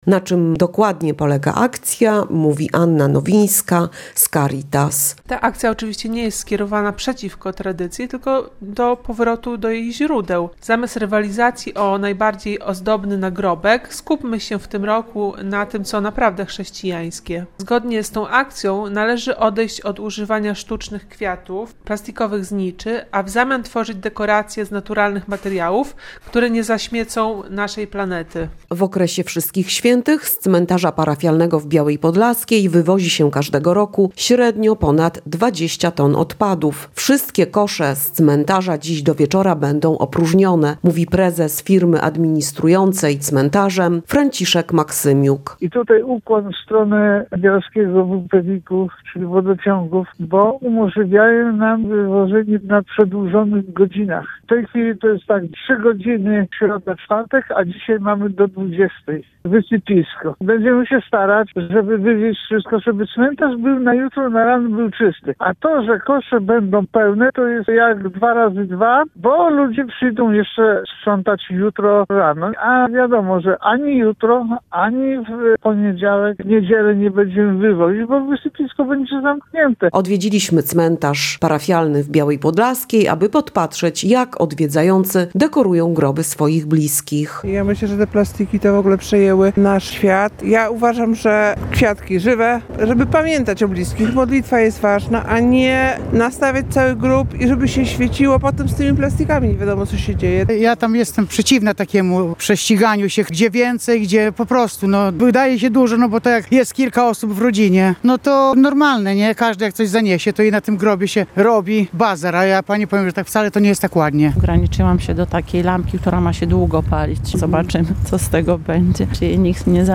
Byliśmy na cmentarzu parafialnym w Białej Podlaskiej, aby sprawdzić czy odwiedzający stosują się do tych zasad: – Myślę, że plastiki przejęły nasz świat.